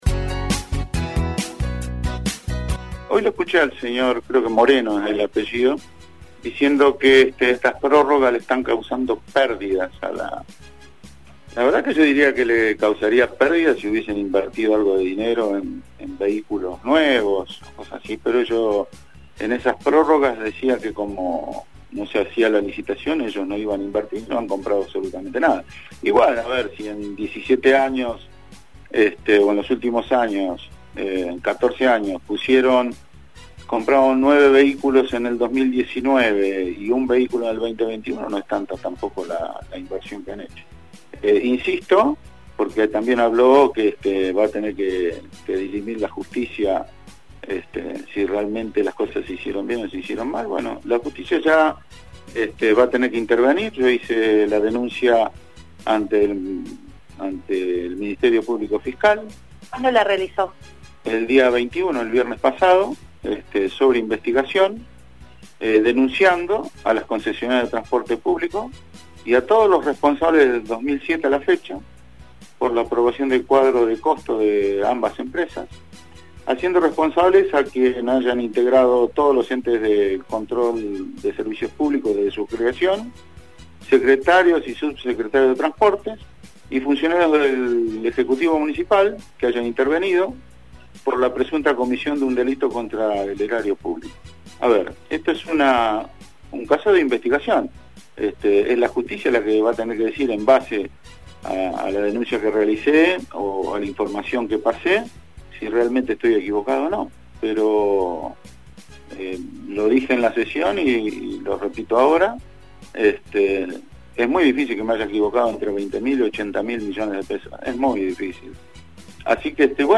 El concejal Omar Lattanzio del bloque del PLICH, emitió duros conceptos a través de Radiovision: “la empresa Patagonia Argentina está haciendo un apriete a toda a la ciudad”, señaló.